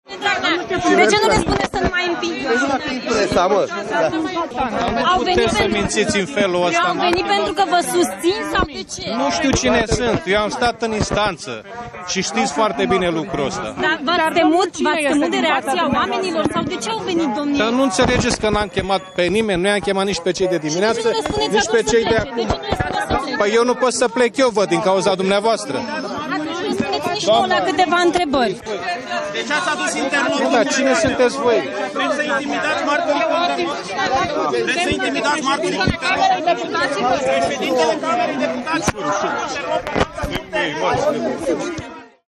03oct-18-Dragnea-ambianta-la-iesirea-de-la-ICCJ.mp3